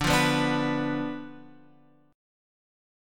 D Minor 6th